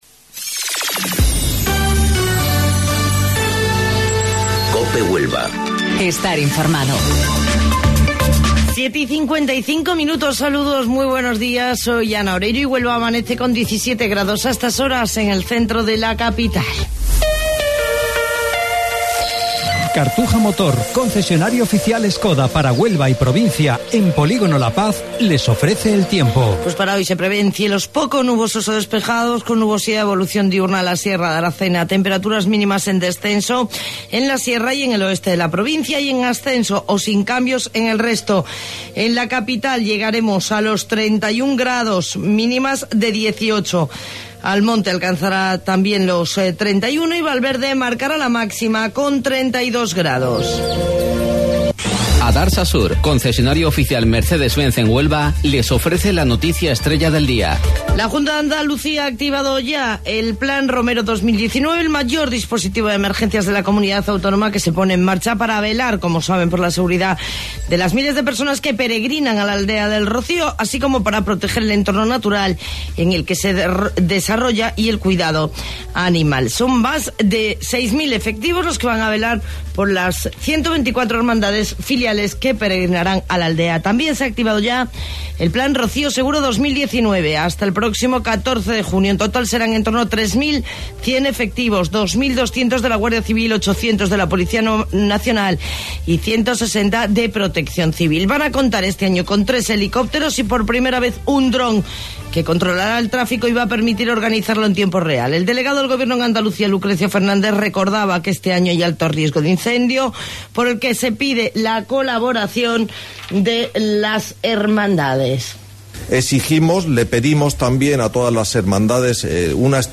AUDIO: Informativo Local 07:55 del 4 de Junio